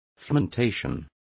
Complete with pronunciation of the translation of fermentation.